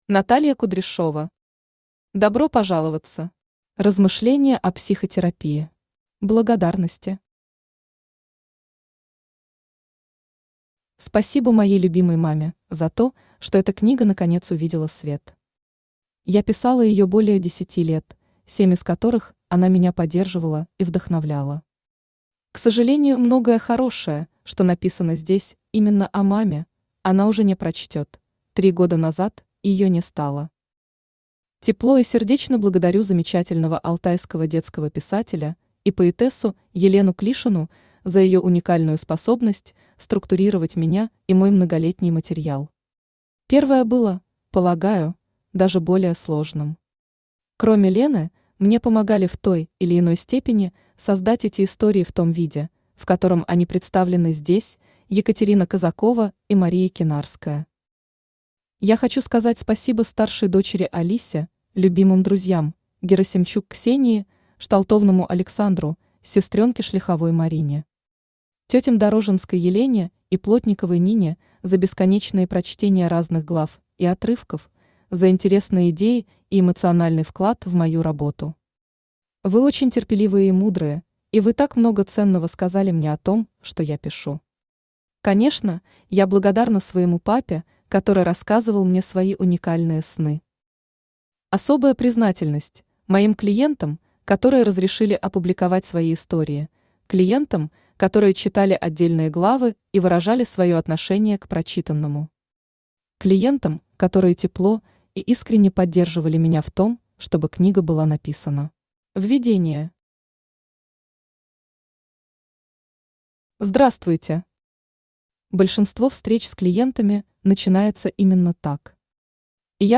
Аудиокнига Добро пожаловаться! Размышления о психотерапии | Библиотека аудиокниг
Размышления о психотерапии Автор Наталья Кудряшова Читает аудиокнигу Авточтец ЛитРес.